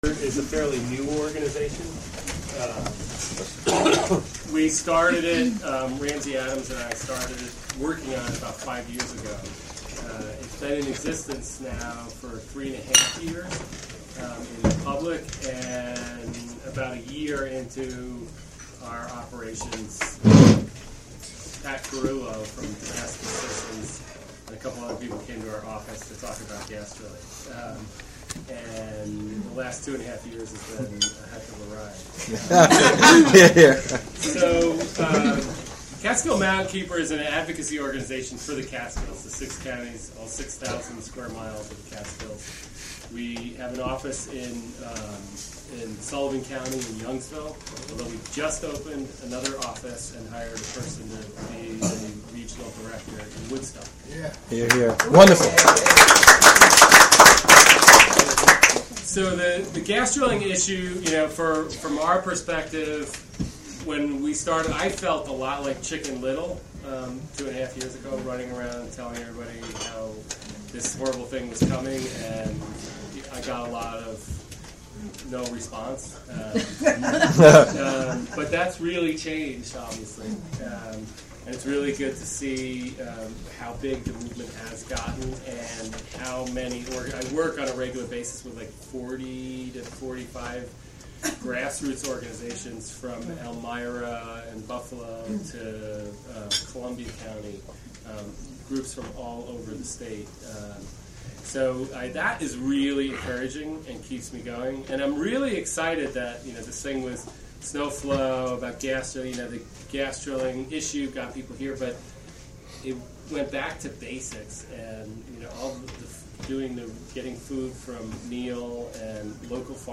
Recorded during the dinner at the SnowFlow festival in Ulster County at the Full Moon Resort.